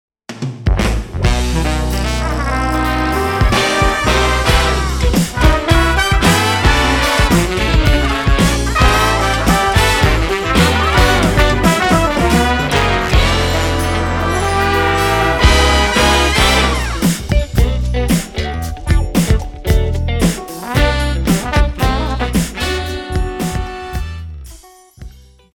סיפריית דגימות של כלי נשיפה, GB100 ! הייתי מוכן להמר על כל מה שיש לי (ושאין לי!)שאלו כלים אמיתיים...אבל זה לא.